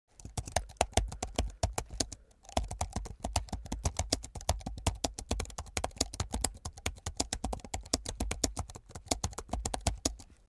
دانلود صدای کیبورد 15 از ساعد نیوز با لینک مستقیم و کیفیت بالا
جلوه های صوتی